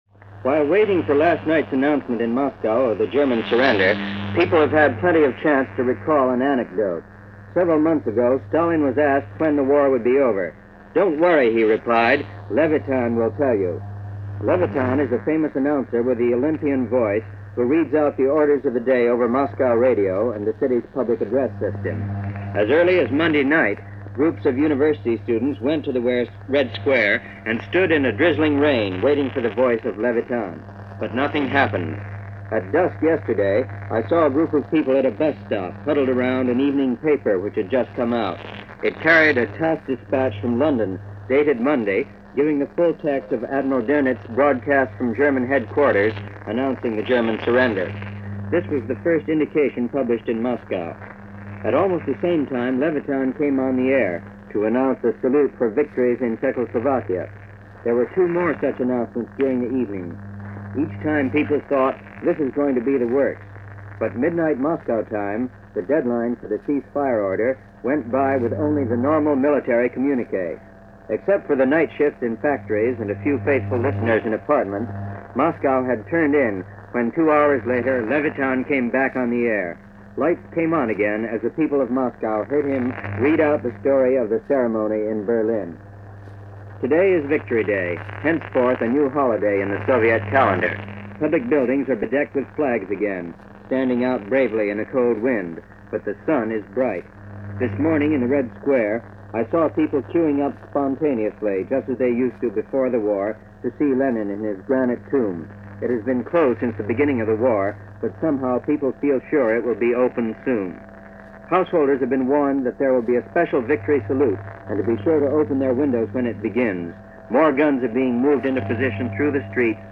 May 9, 1945 - V-E Day - The View From Moscow - news reports from Moscow on the "official" end of the War in Europe, according to Moscow.
Reports-from-Moscow-May-9-1945.mp3